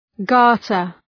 {‘gɑ:rtər}